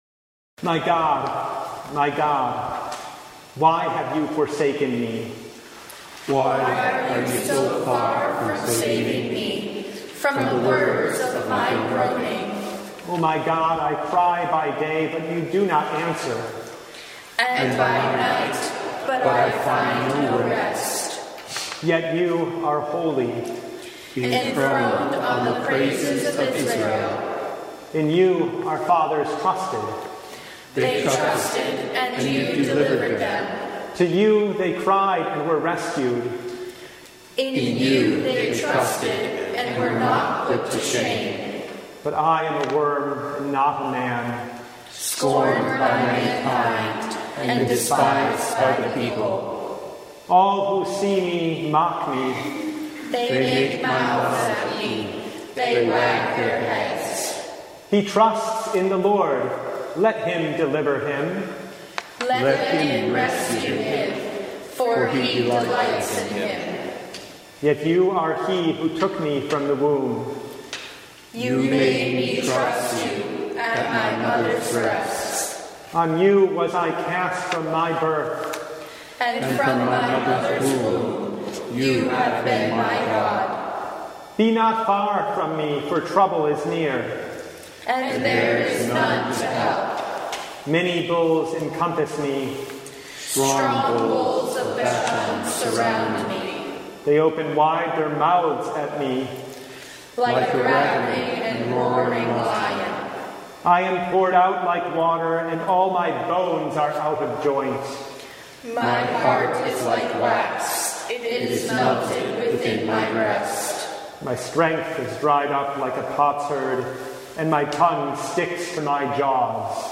Service Type: Good Friday Tenebrae
Download Files Notes Bulletin Topics: Full Service « At the Heart of It All—His Love!